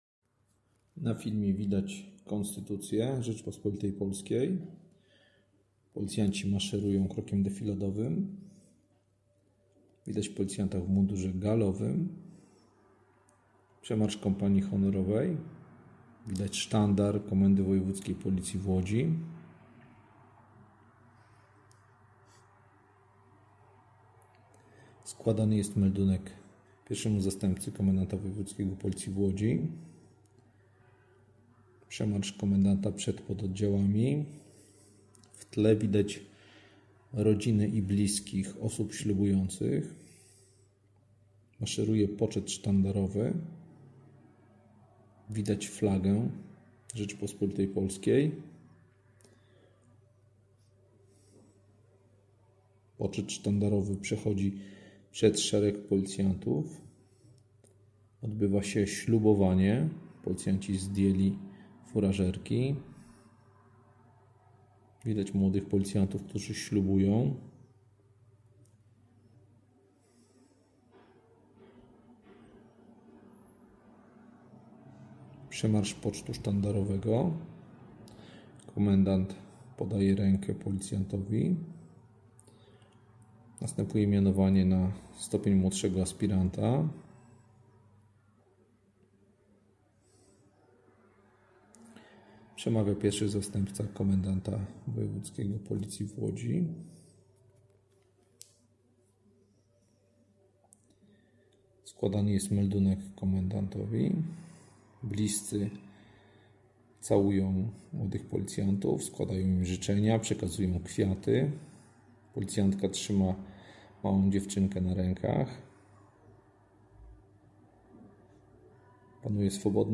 Następnie odtworzono Hymn RP i przywitano przybyłych gości.
Nastąpił podniosły moment, podczas którego przyjmujący ślubowanie insp. Jarosław Rybka I Zastępca Komendanta Wojewódzkiego Policji w Łodzi, odczytał rotę ślubowania, którą powtórzyli za nim ślubujący policjanci.
Nadszedł czas na przemówienia.
Głos zabrali również Sekretarz Województwa Łódzkiego Robert Kolczyński oraz Wiceprezydent Miasta Łodzi Adam Wieczorek, którzy pogratulowali nowo przyjętym policjantom decyzji o podjęciu służby na rzecz dobra mieszkańców województwa łódzkiego życząc im wytrwałości i powodzenia w służbie.